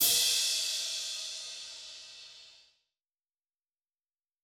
Perc (11).wav